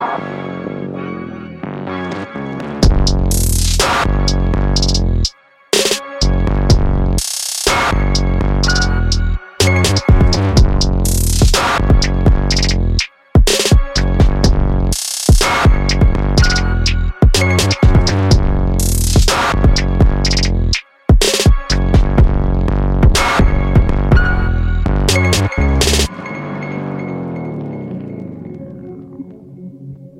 Tape-infused textures
用于尖端制作的复古声音
它充满了个性和好奇心，提供了不拘一格的旋律乐器系列，所有这些都通过高端机架齿轮、老式磁带机和精品踏板运行。
从复古键盘和模块化合成器引线到电子弓吉他、古董风琴和木管乐器，它拥有为现代制作带来复古风情所需的一切。
音频效果试听